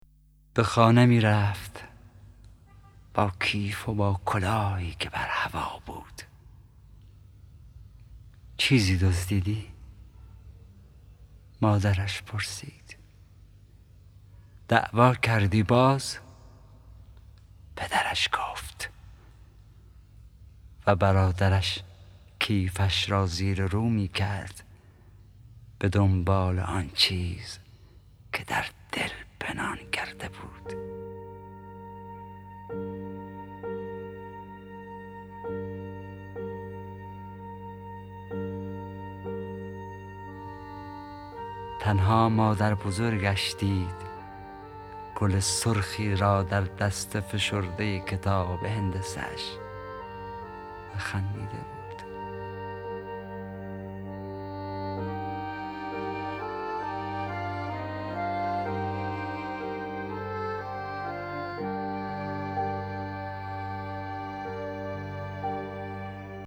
دانلود دکلمه به خانه می رفت با صدای حسین پناهی
گوینده :   [حسین پناهی]